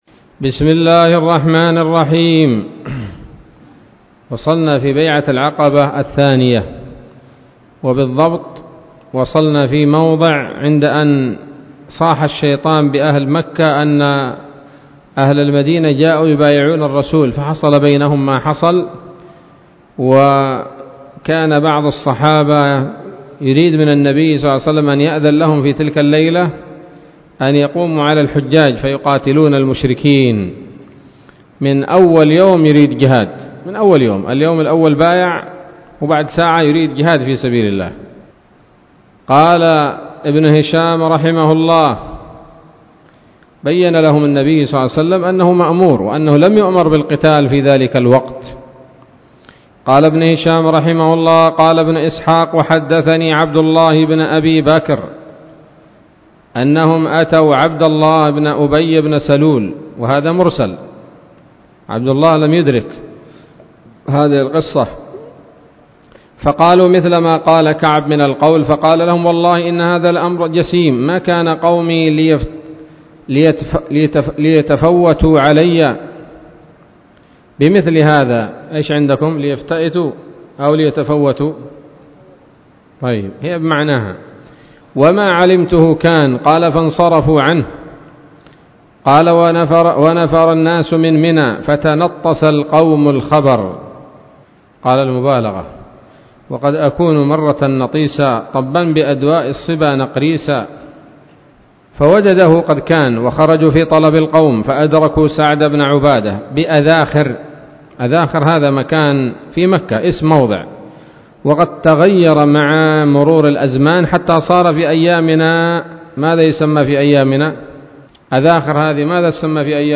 الدرس الخامس والستون من التعليق على كتاب السيرة النبوية لابن هشام